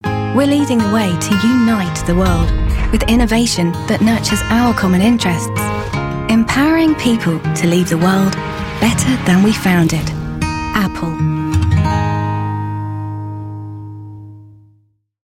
She is softly spoken and easy on the ears.
british english
authoritative
Apple_Imaging_MX.mp3